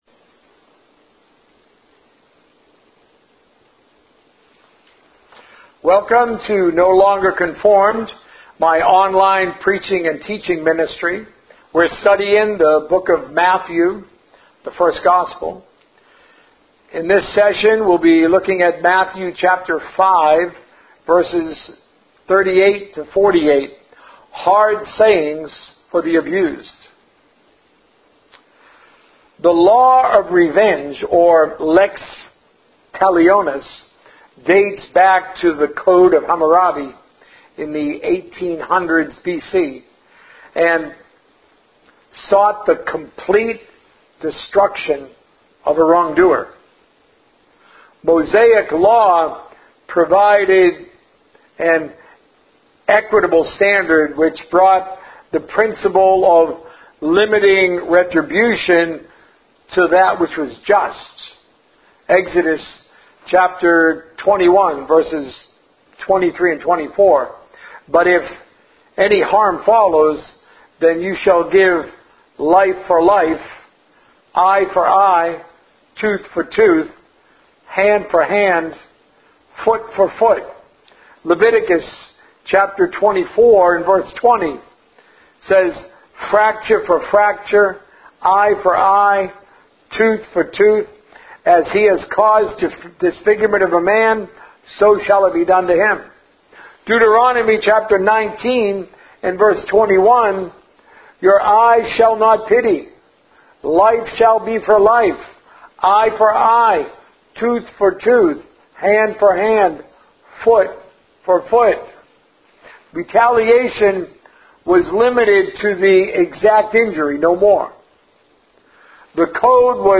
A message encouraging debt-free living